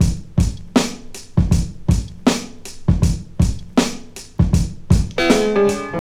Whoa Drums.wav